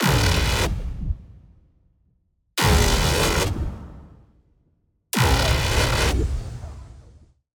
Impact Blast 3
Impact-Blast-03-Example.mp3